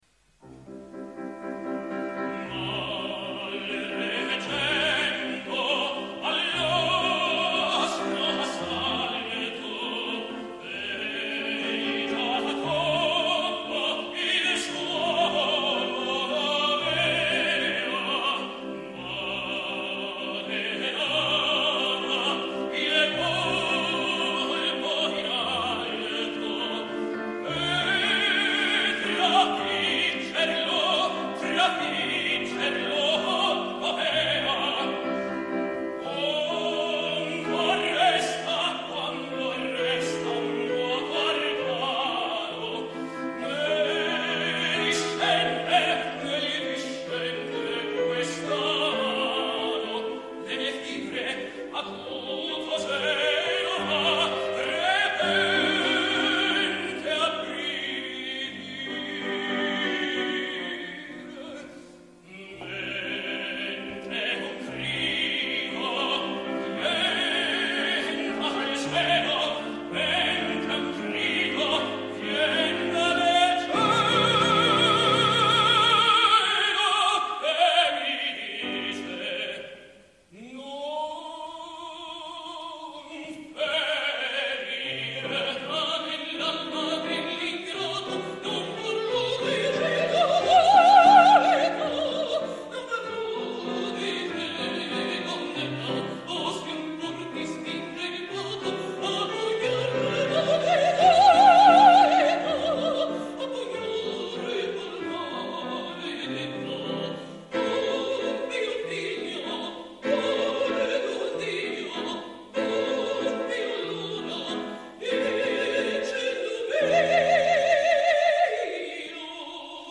The below recording is from 2015, and everyone can hear what happened to her voice in the interval of just seven years; that she destroyed it is thus no insinuation but an unquestionable fact.